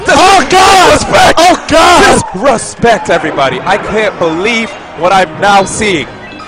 Tags: Super Smash Bros Melee Games Commentary